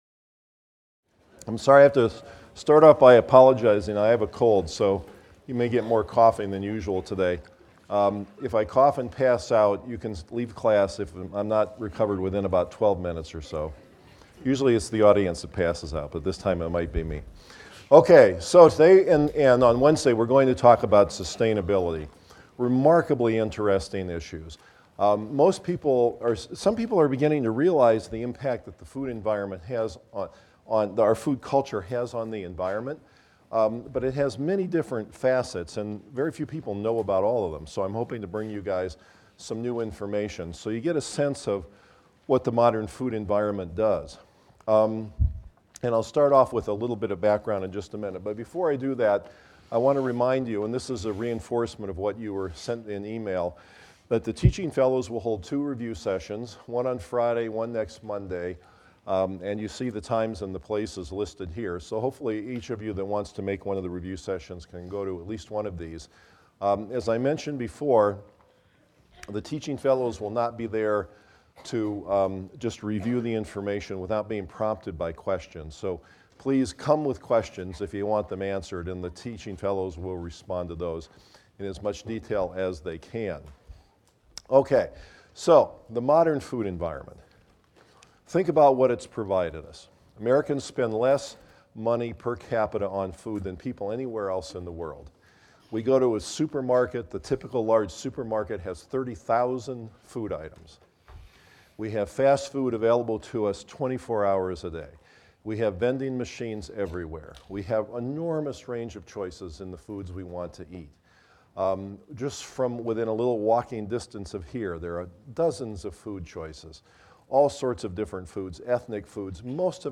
PSYC 123 - Lecture 10 - Sustainability I: The Impact of Modern Agriculture on the Environment and Energy Use | Open Yale Courses